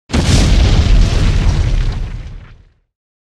Tower Die.mp3